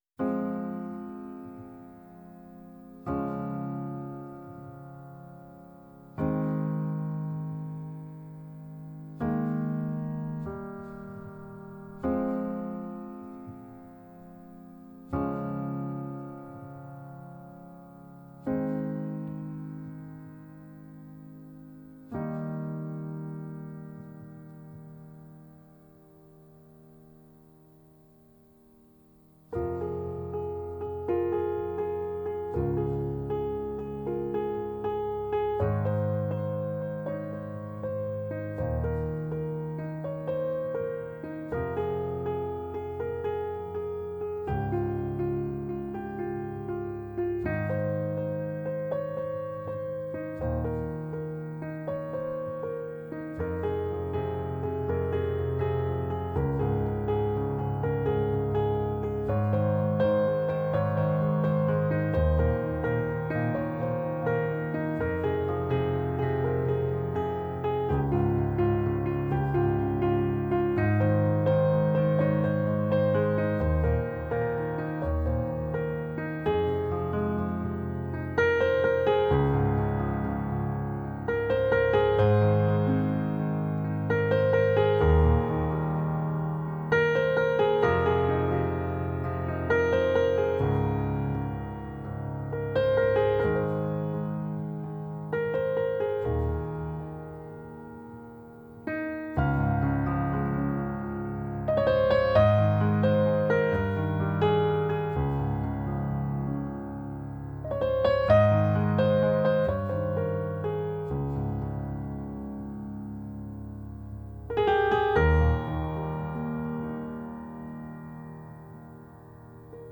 музыка без слов